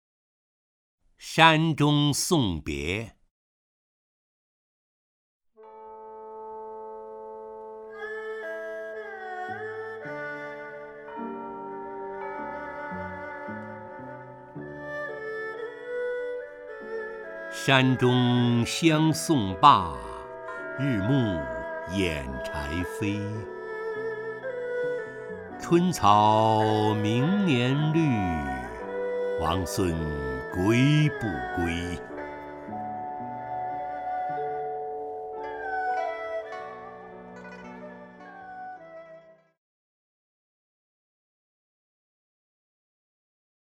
陈醇朗诵：《山中送别》(（唐）王维) (右击另存下载) 山中相送罢， 日暮掩柴扉。